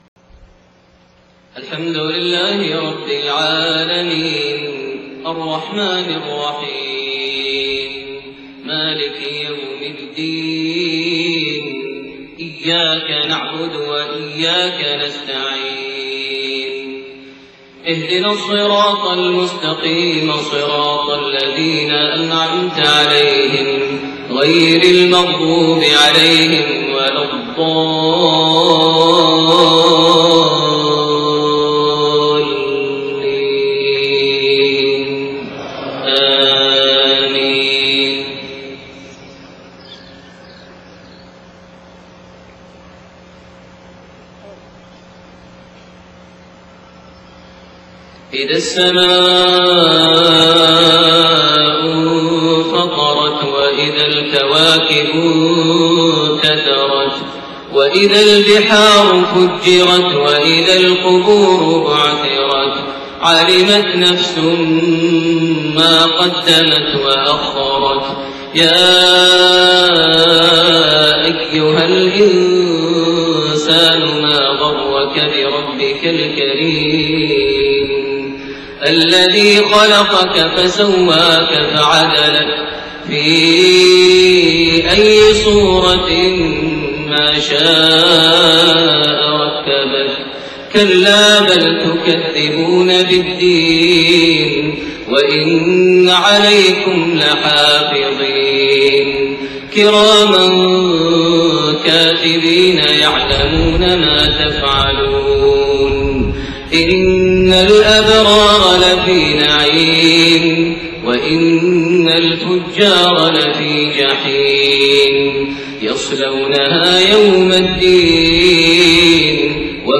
صلاة المغرب8-2-1431 سورتي الانفطار والقارعة > 1431 هـ > الفروض - تلاوات ماهر المعيقلي